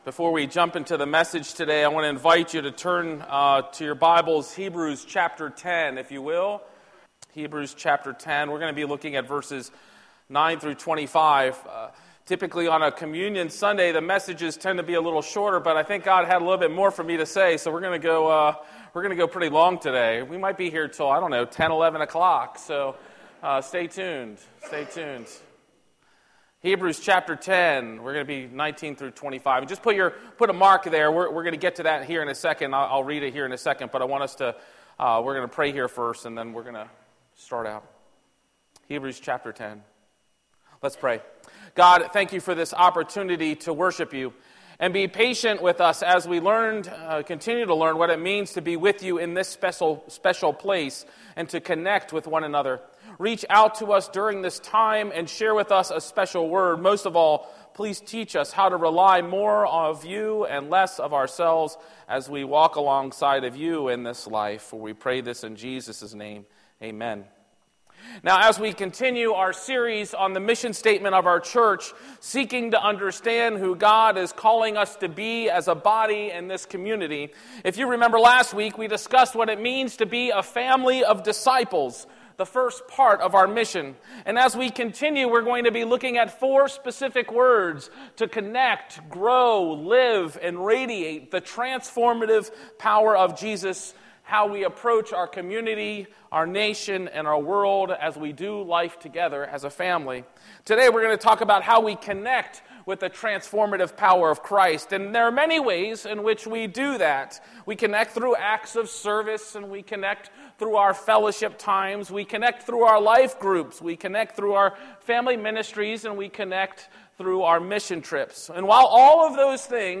A message from the series "We Are...."